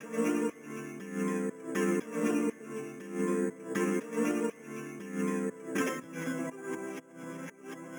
Raggio_120_F_FX.wav